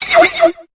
Cri de Blizzi dans Pokémon Épée et Bouclier.